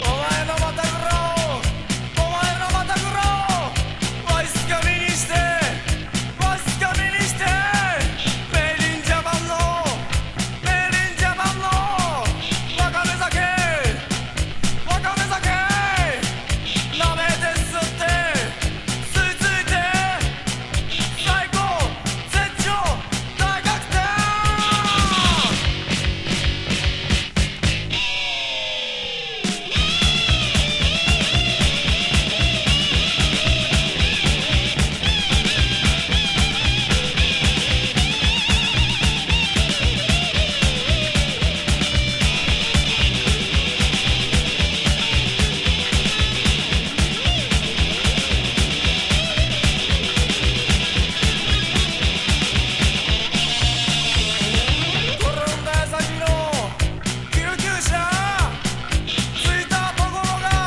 ’88年自主インディーバンド編集盤。